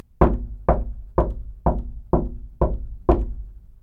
دانلود صدای در چوبی 6 از ساعد نیوز با لینک مستقیم و کیفیت بالا
جلوه های صوتی
برچسب: دانلود آهنگ های افکت صوتی اشیاء دانلود آلبوم صدای کوبیدن در چوبی از افکت صوتی اشیاء